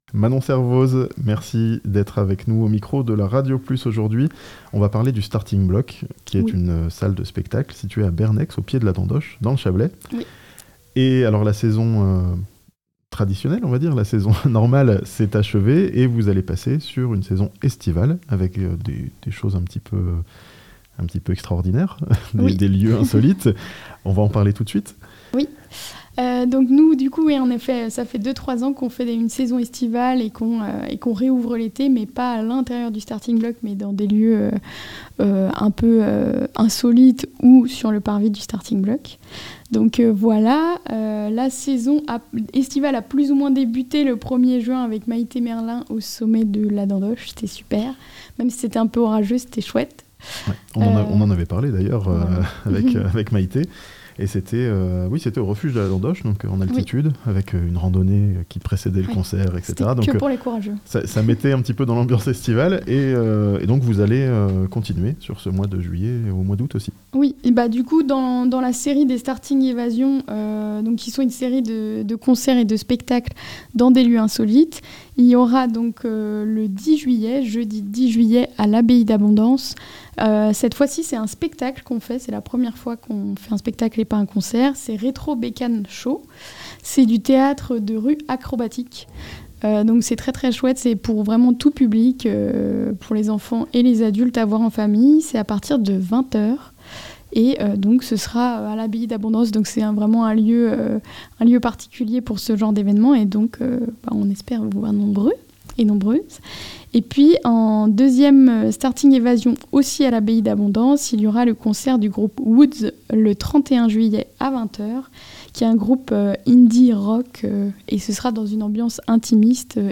Au Starting Block, le spectacle continue dans des lieux insolites cet été (interview)